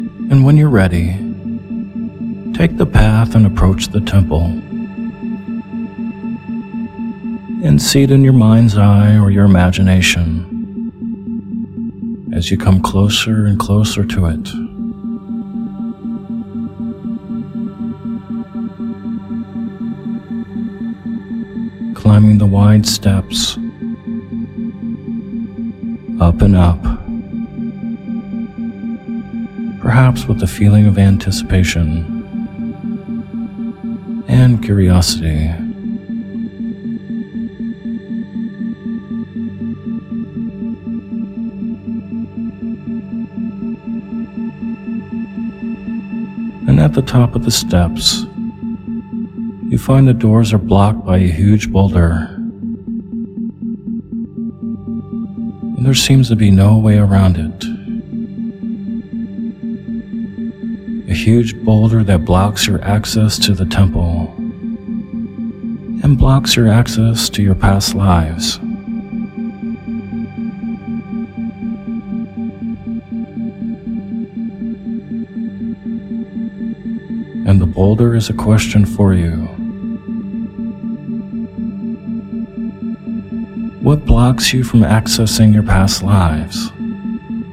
Guided Meditation For Past Life Regression (Temple Of Ages) W/ Isochronic Tones